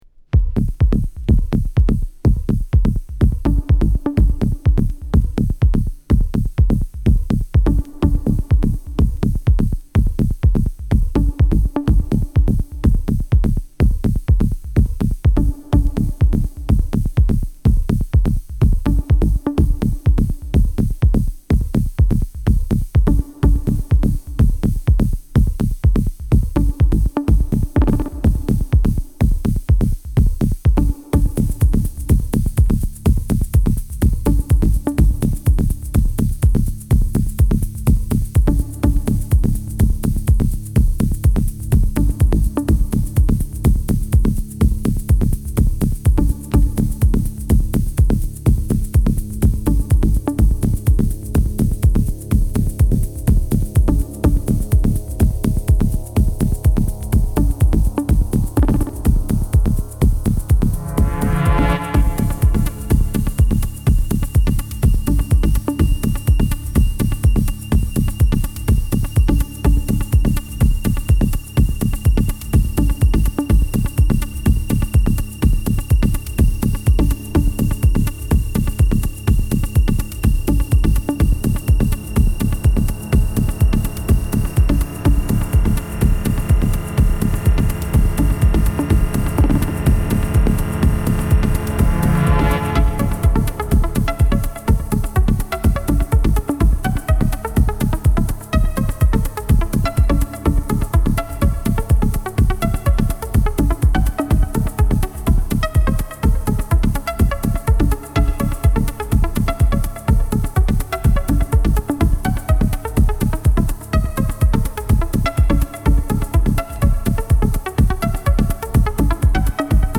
ベルリンをベースに活躍するDJデュオ